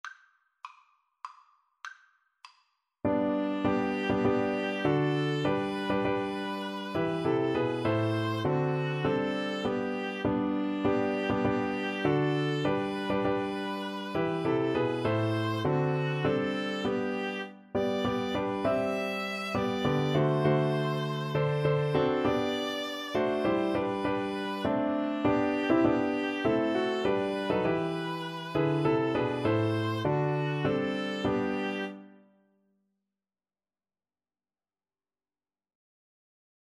Free Sheet music for Piano Trio
G major (Sounding Pitch) (View more G major Music for Piano Trio )
3/4 (View more 3/4 Music)
Traditional (View more Traditional Piano Trio Music)